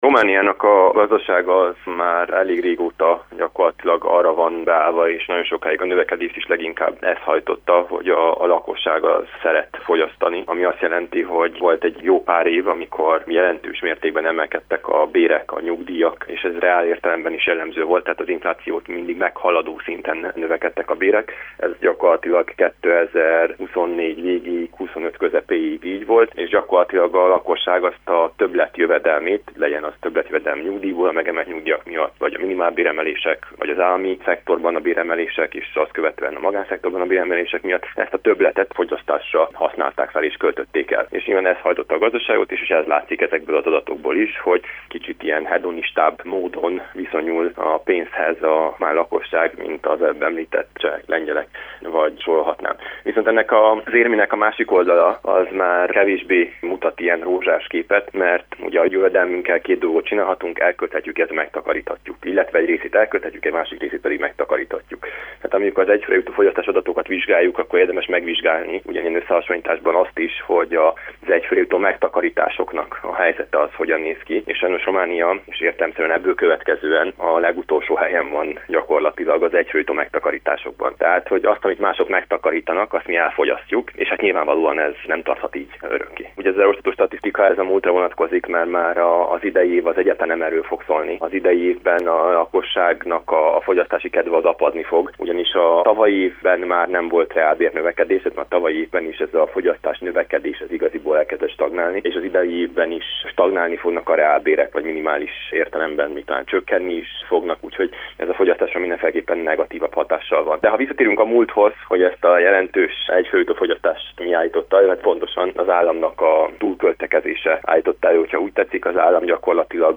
szakemberrel beszélgettünk a 2026-os év gazdasági kilátásairól.